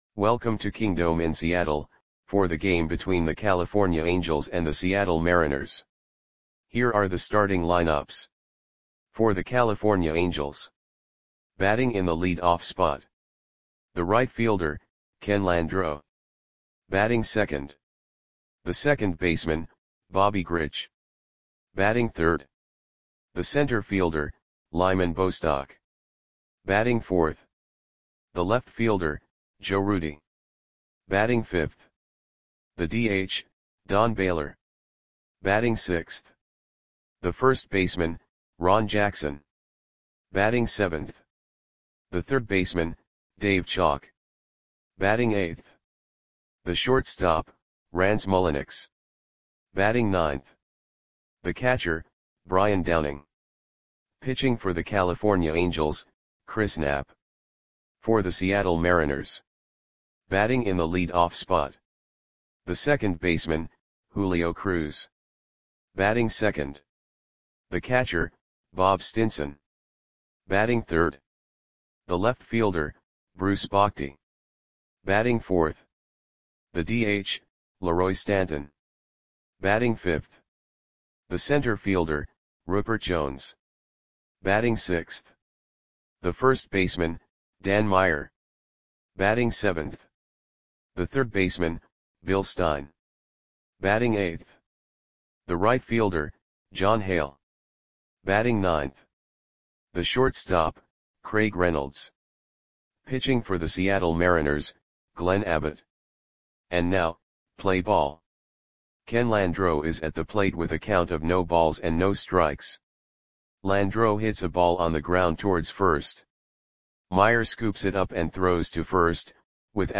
Lineups for the Seattle Mariners versus California Angels baseball game on April 19, 1978 at Kingdome (Seattle, WA).
Click the button below to listen to the audio play-by-play.